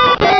Cri de Coxyclaque dans Pokémon Rubis et Saphir.